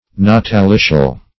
Search Result for " natalitial" : The Collaborative International Dictionary of English v.0.48: Natalitial \Na`ta*li"tial\, Natalitious \Na`ta*li"tious\, a. [L. natalitius, from natalis.